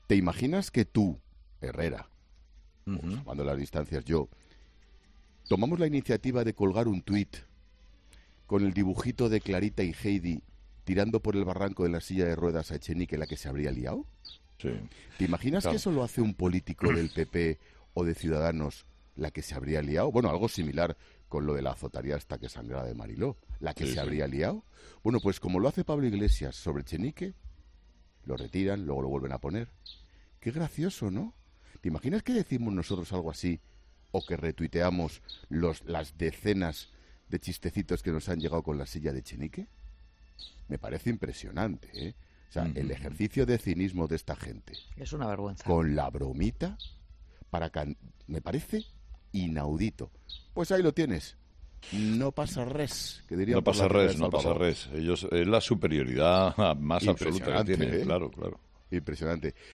El tuit generó mucha controversia y durante unas horas fue retirado. En su intervención este jueves en "Herrera en COPE", Ángel Expósito ha analizado esta circunstancia.
Herrera ha dado la razón a Expósito y ha dicho: "Es la superioridad moral más absoluta".